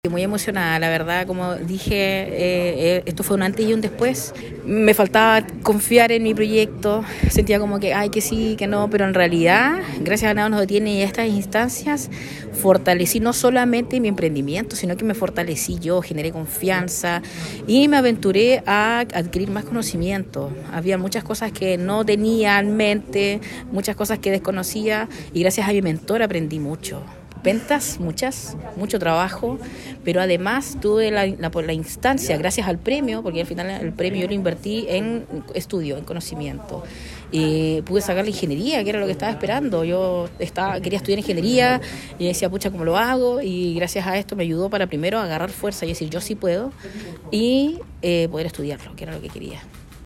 En Sala de Sesiones se realizó el lanzamiento de la segunda versión del Concurso de Emprendimiento “Nada Nos Detiene”, que ejecuta la Corporación G-100, con la colaboración del municipio de Osorno y el patrocinio del “Grupo de Empresas Feria Osorno”, y que premiará con $3 millones de pesos al ganador.